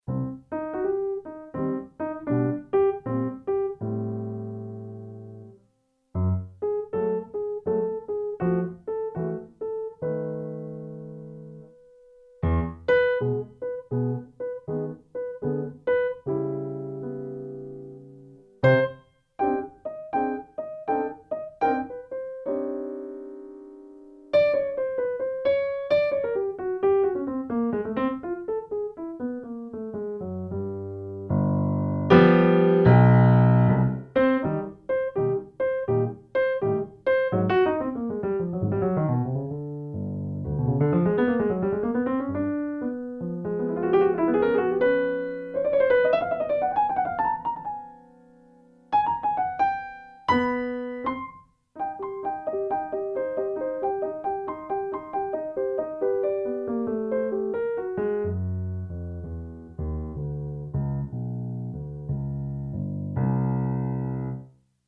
It's a somewhat tired and dizzy sounding piano improvisation with a slightly retro swing feeling.